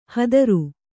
♪ hadaru